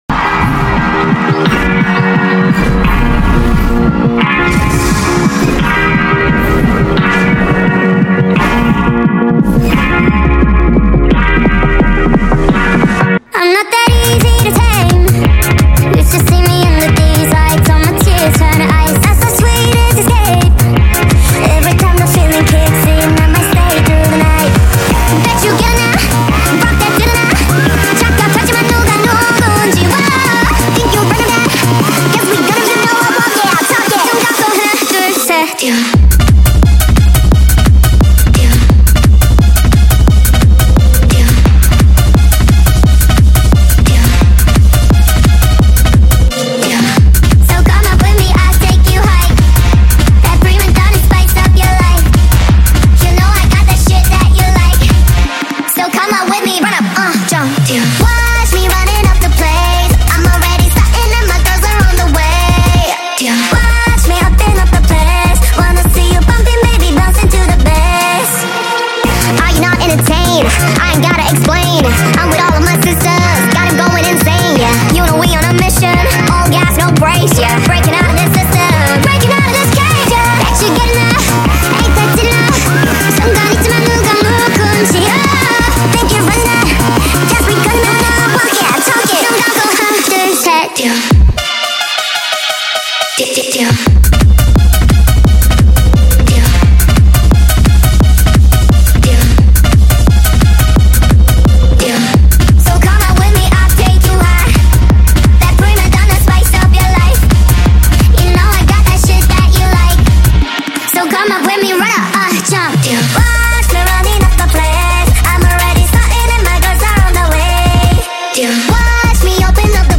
SPEED UP FULL SONG